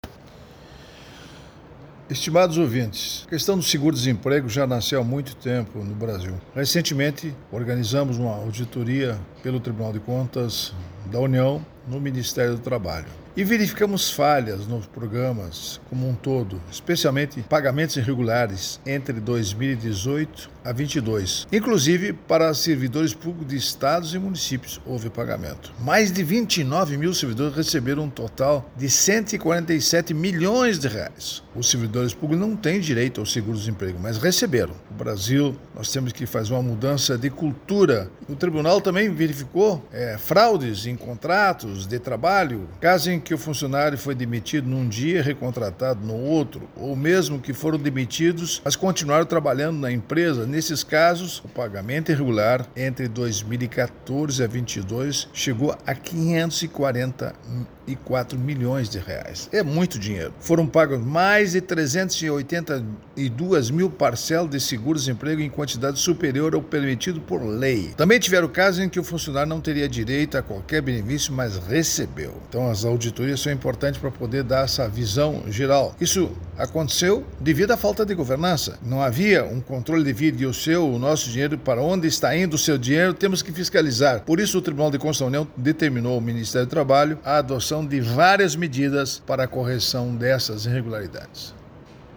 Comentário desta sexta-feira (18/10/24) do ministro do TCU Augusto Nardes.